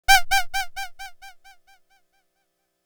Rubber Ducky 1.wav